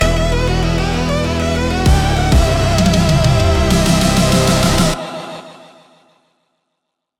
cool